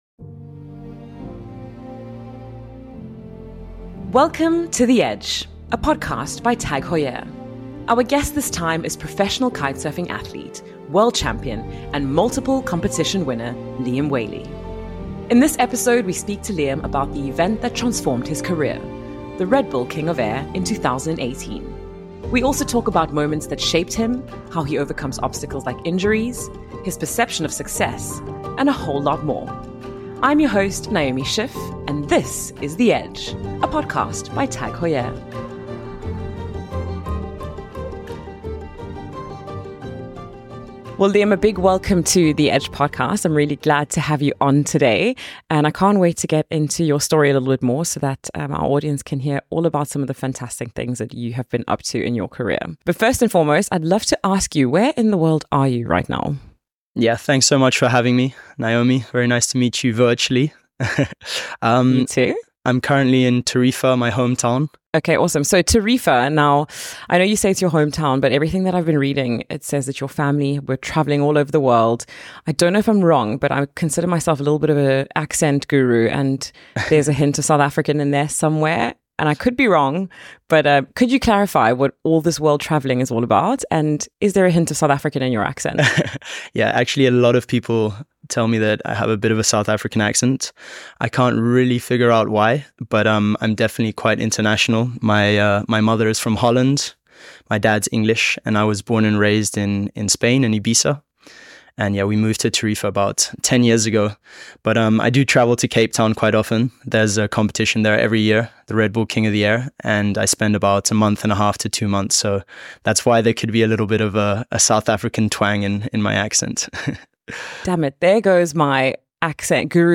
Our guest today is Liam Whaley, professional kitesurfing athlete, world champion and multiple competition winner.
Liam also talks about the moments that shaped him, how he overcomes obstacles and injuries, his perception of success and a whole lot more. Presented by your host Naomi Schiff, this is The Edge, a podcast by TAG Heuer.